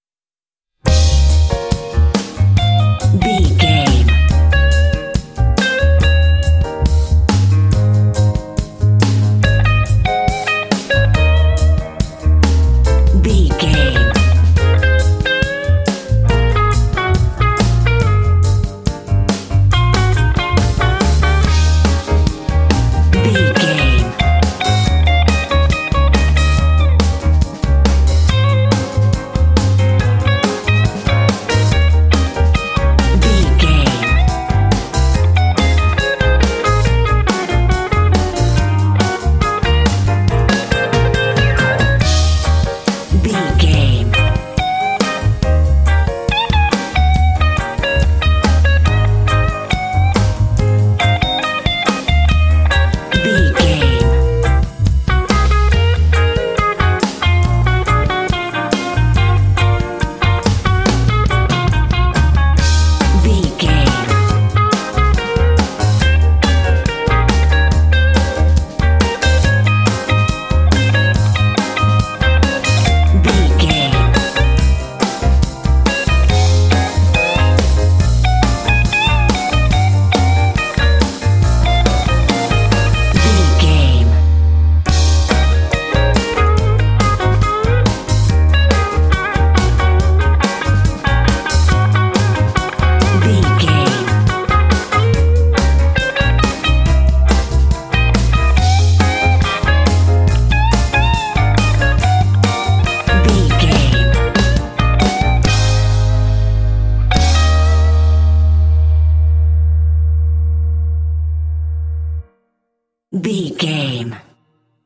Aeolian/Minor
E♭
funky
groovy
bright
piano
drums
electric guitar
bass guitar
blues
jazz